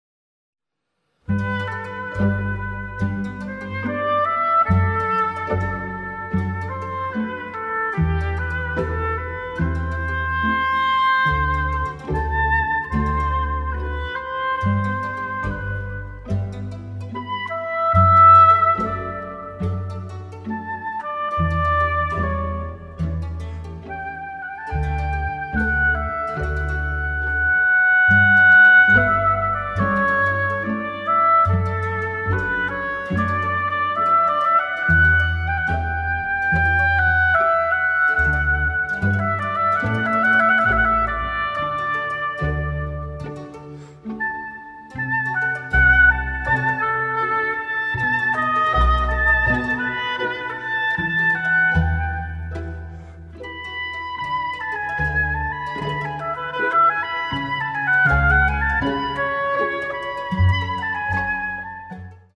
for oboe, strings and bc
Andante 2:54
oboe